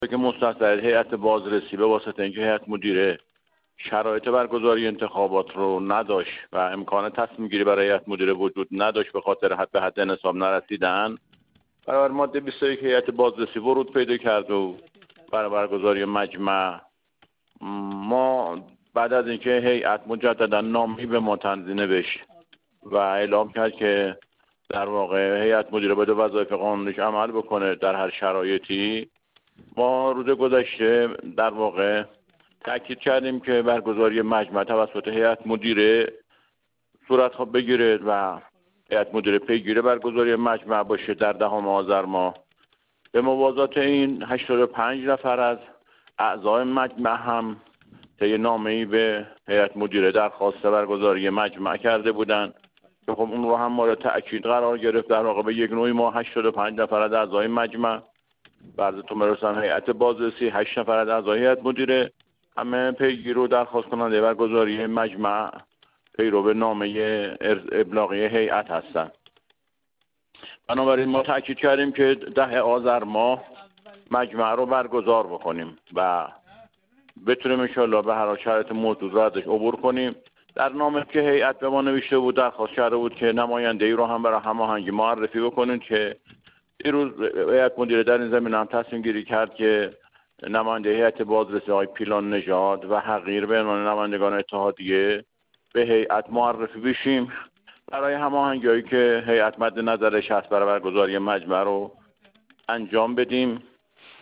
در گفت‌وگو با ایکنا